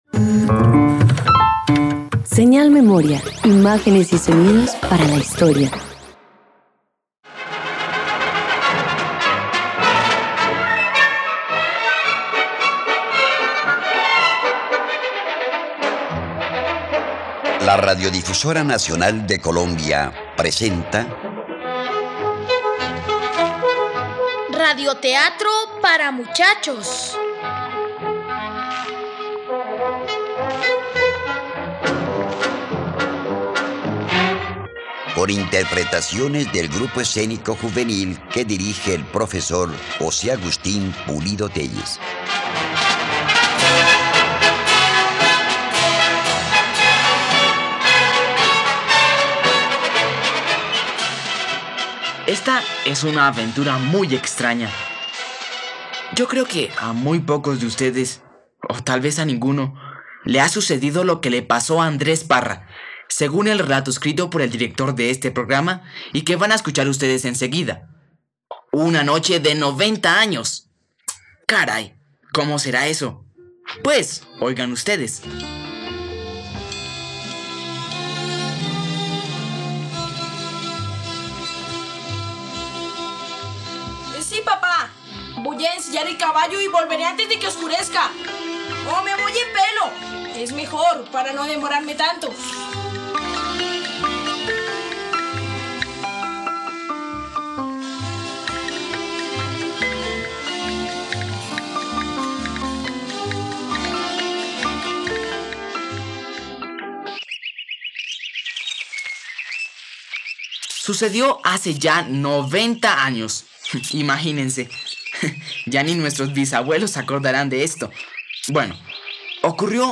Una noche de noventa años - Radioteatro dominical | RTVCPlay